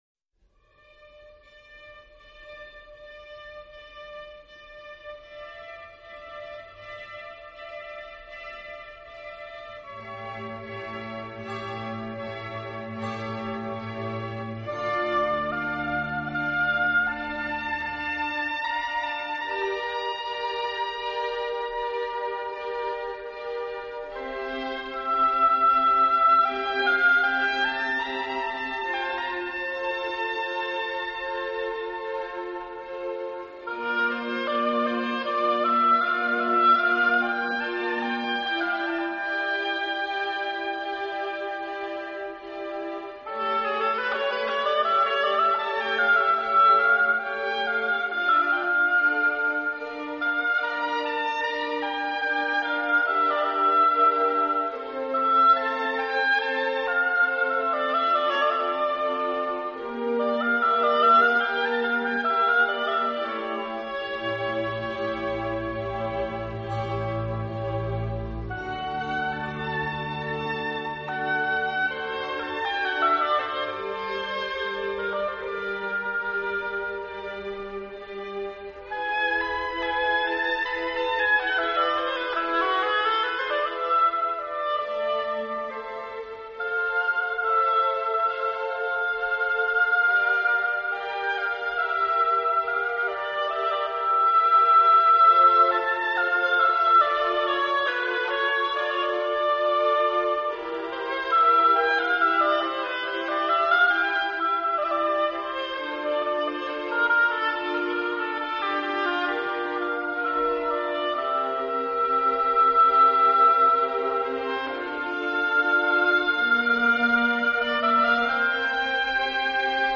类型: 减压音乐
这些曲目都是旋律优美，雅俗共赏的经典。